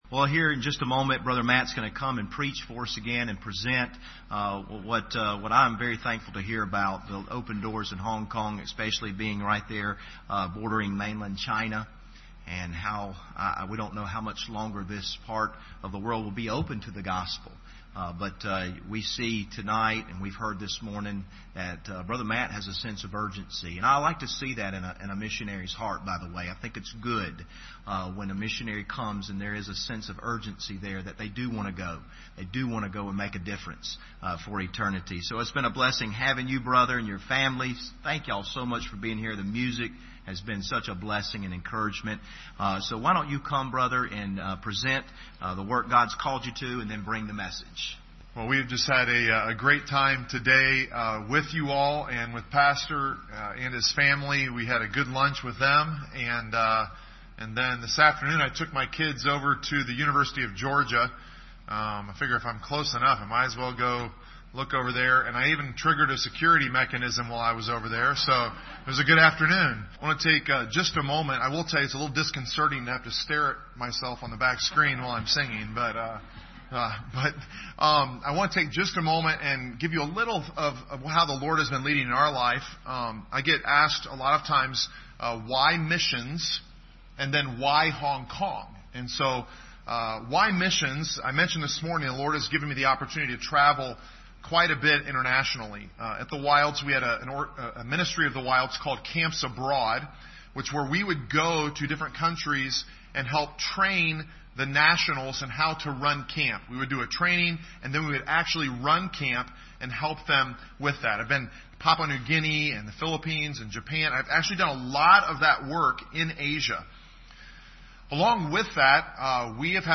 Missionary Presentation
Service Type: Sunday Evening